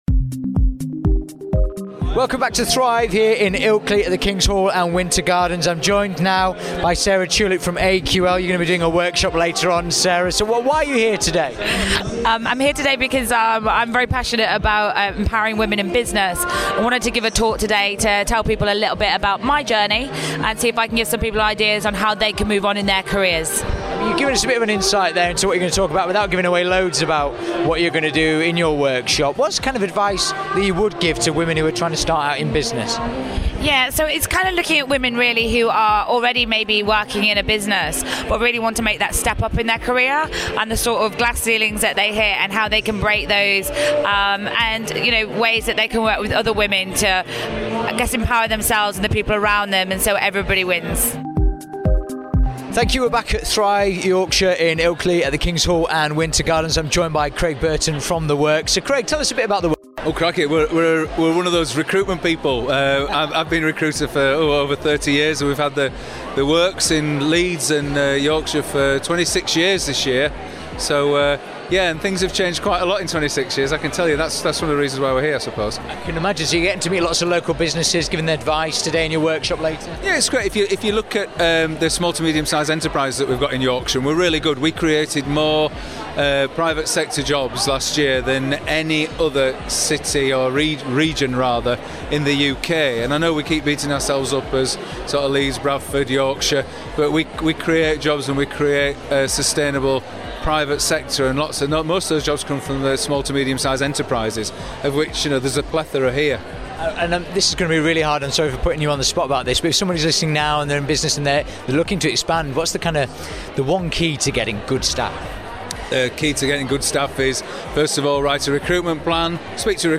Local businesses from Yorkshire came together at the Winter Gardens in Ilkley to talk, learn and expand in their business worlds.